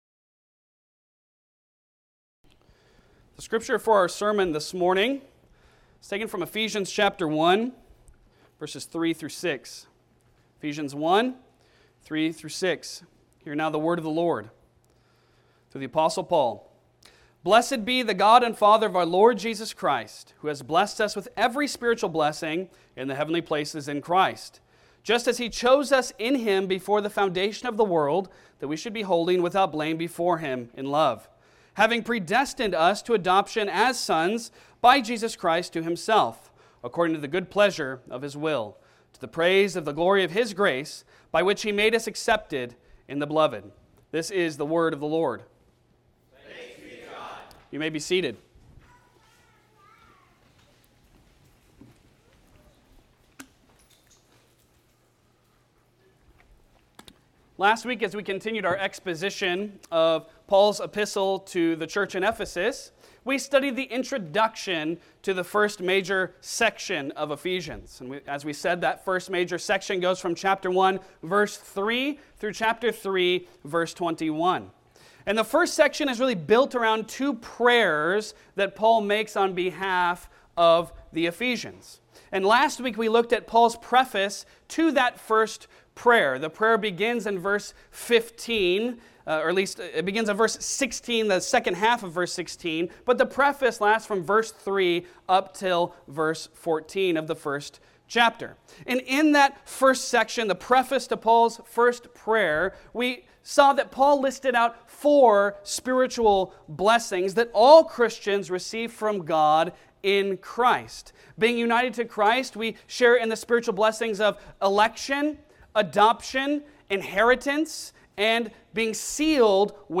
Passage: Ephesians 1:3-6 Service Type: Sunday Sermon